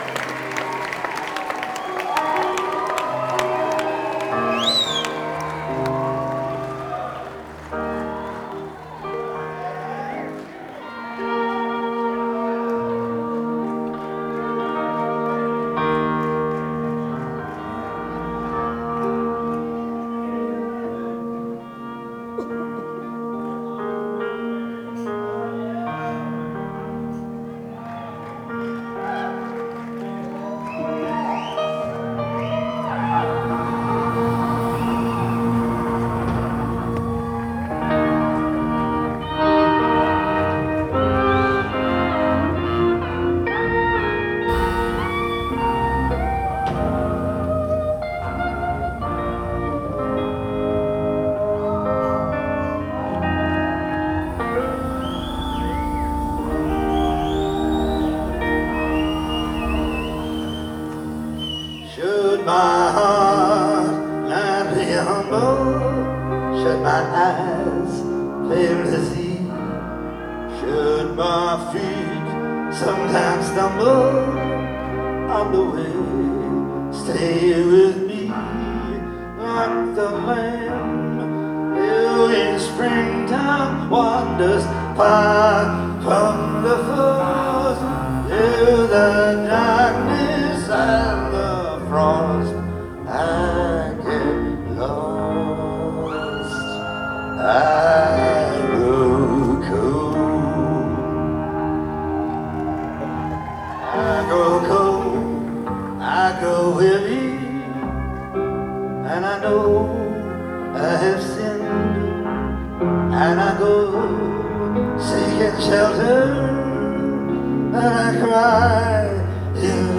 Dolby Theatre - Los Angeles, CA